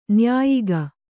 ♪ nyāyiga